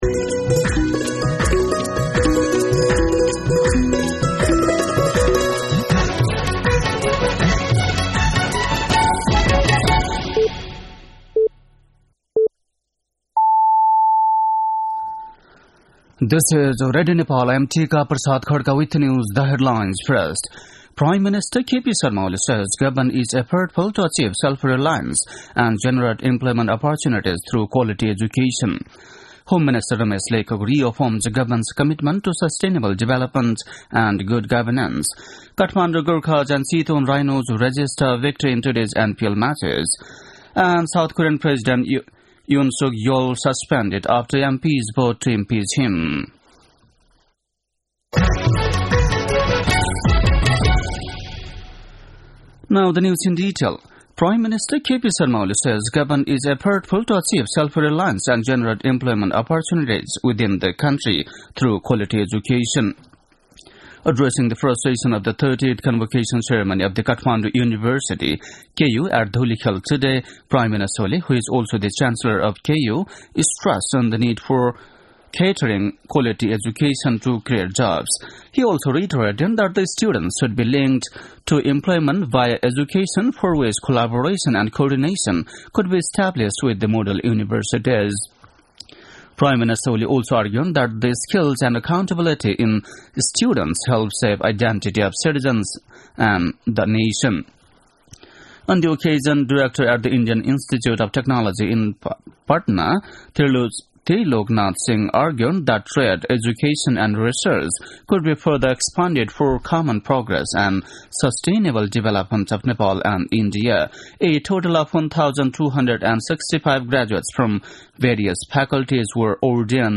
बेलुकी ८ बजेको अङ्ग्रेजी समाचार : ३० मंसिर , २०८१